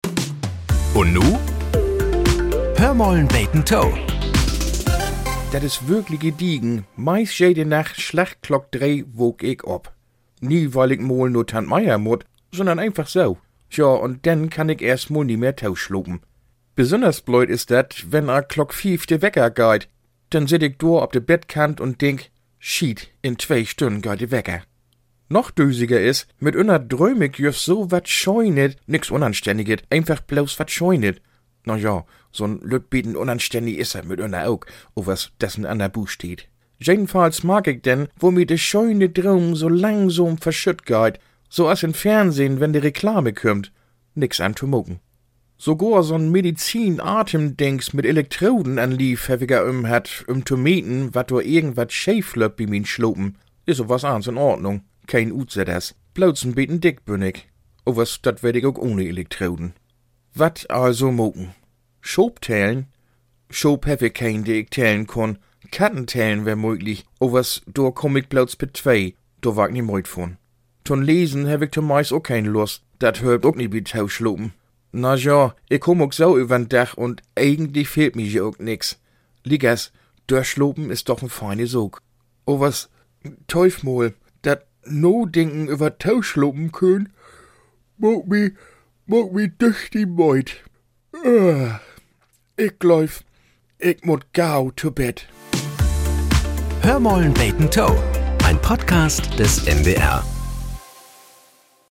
Nachrichten - 29.04.2025